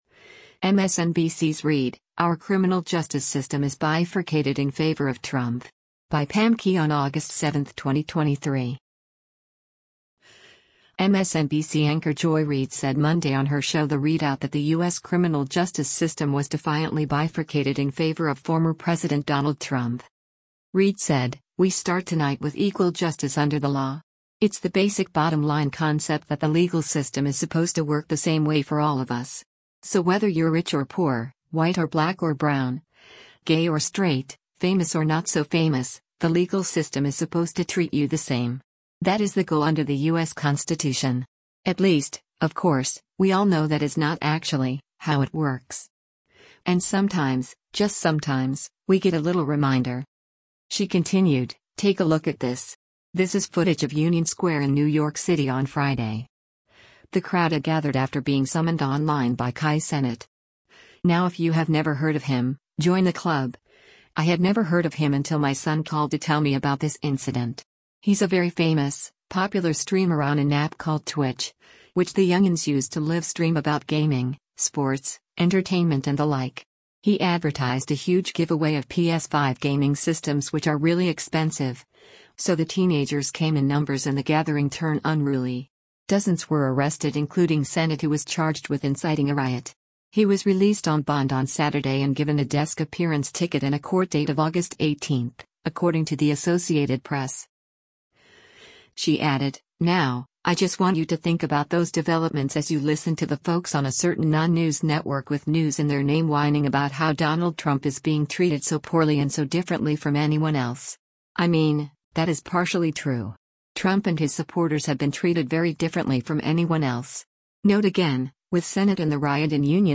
MSNBC anchor Joy Reid said Monday on her show “The ReidOut” that the U.S. criminal justice system was “defiantly bifurcated” in favor of former President Donald Trump.